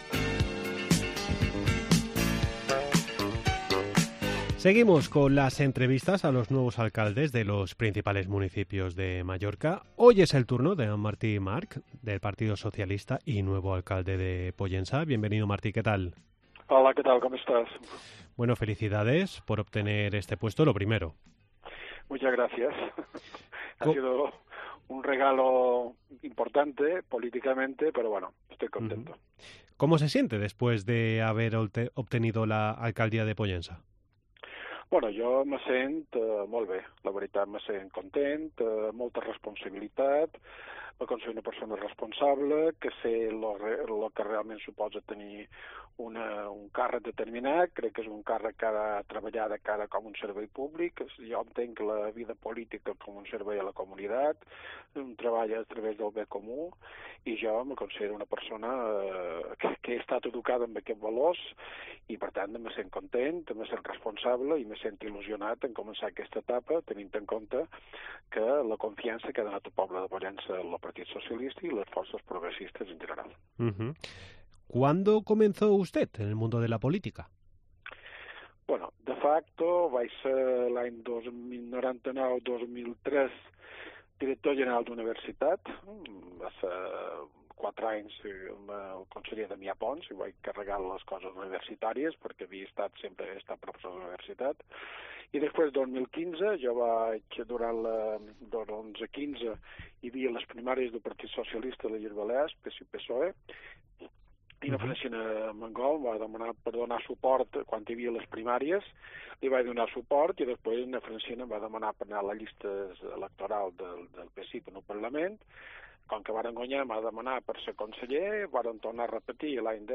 AUDIO: Una semana más continuamos con la ronda de entrevistas a los nuevos alcaldes.